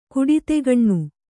♪ kuḍitegaṇṇu